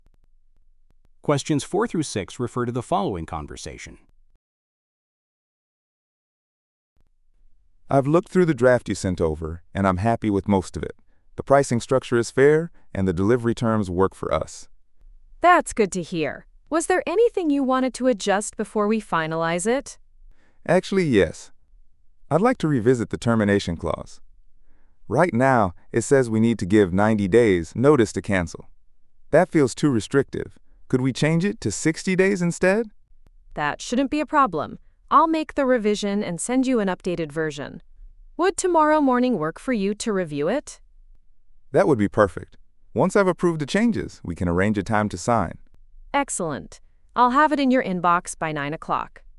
※TOEICは、アメリカ/イギリス/オーストラリア/カナダ発音で出ます。